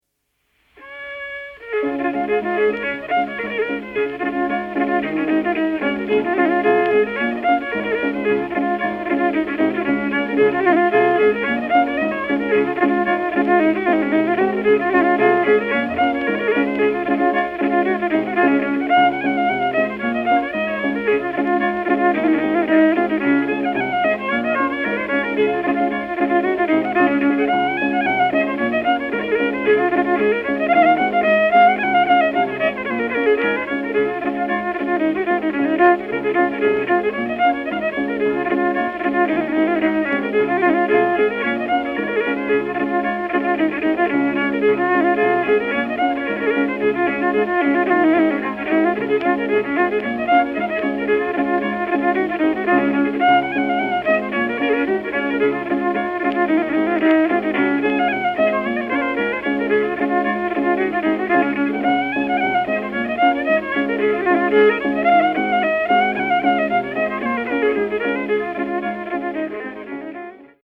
Irish Reel - The Boys of the Loch
Michael Coleman of Remastered recordings
This is a classic Irish reel first recorded by Michael Coleman in 1921. Unlike the written setting below, Coleman varies the melody with every repeat.